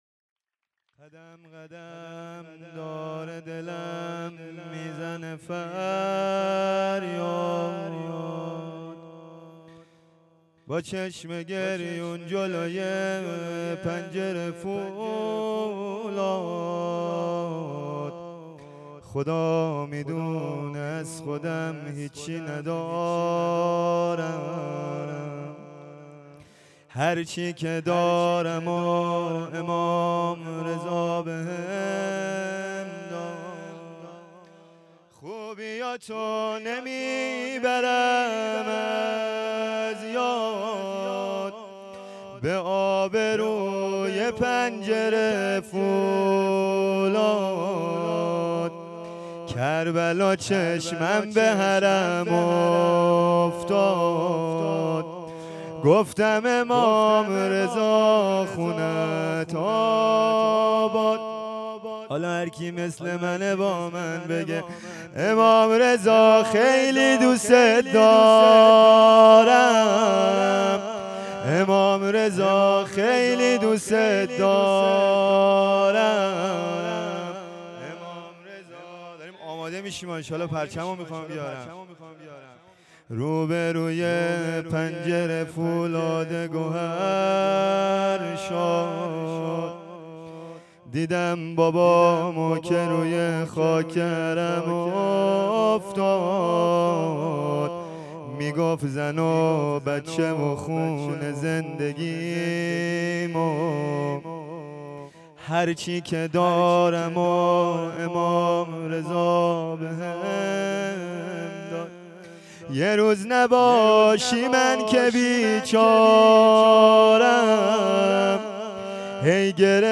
جشن میلاد امام رضا علیه السلام 1404